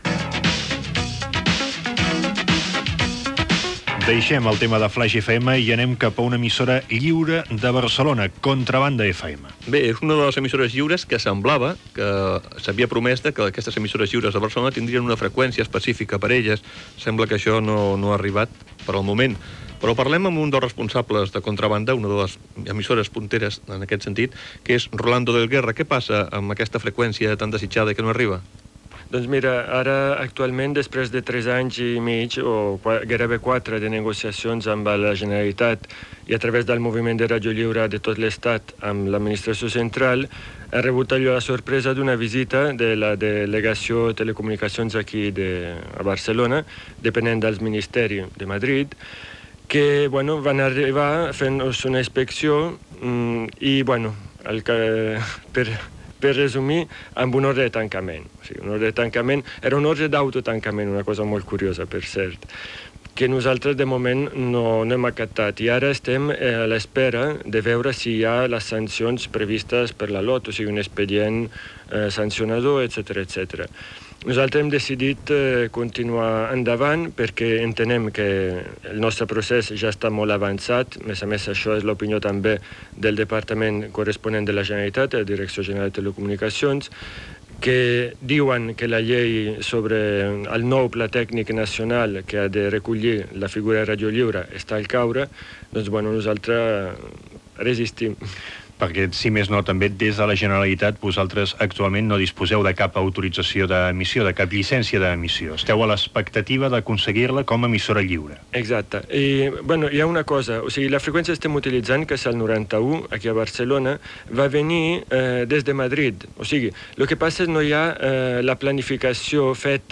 Gènere radiofònic
Divulgació